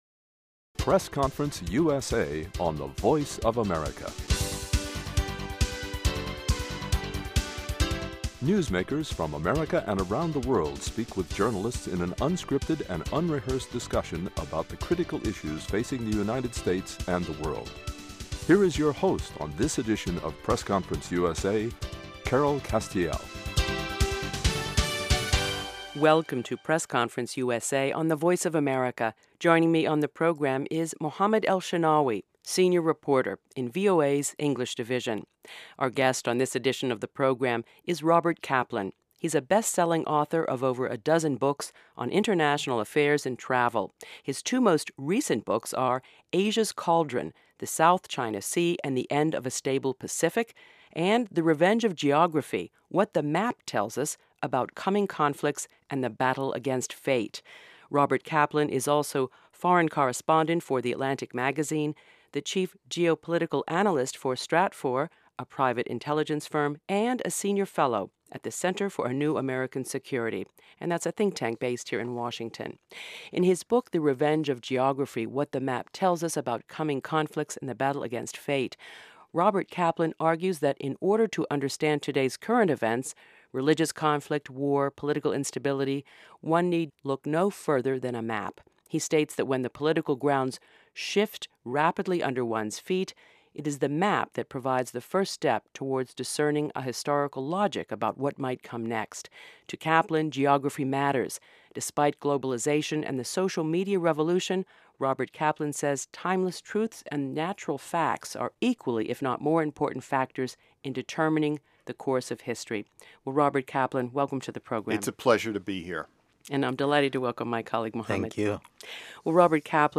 ROBERT KAPLAN - AUTHOR AND SENIOR FELLOW AT CNAS On this edition of the program, a conversation with best-selling author, analyst and foreign correspondent, Robert Kaplan.